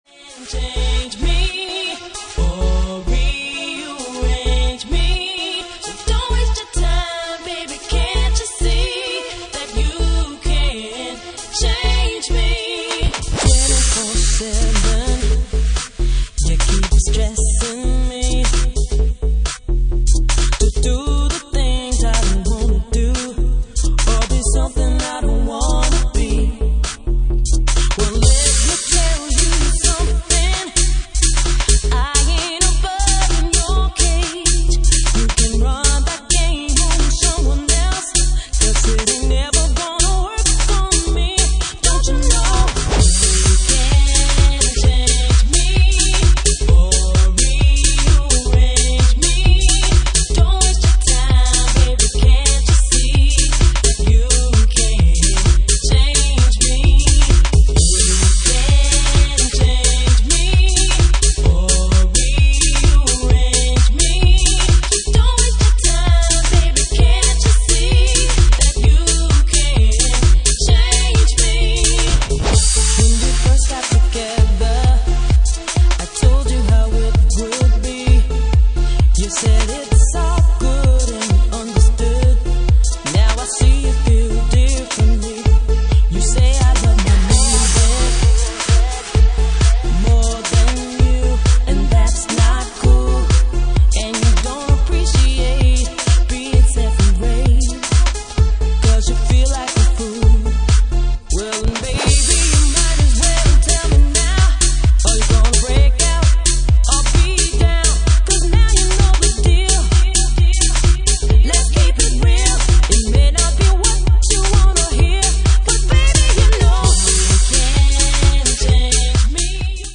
Genre:Jacking House
Jacking House at 131 bpm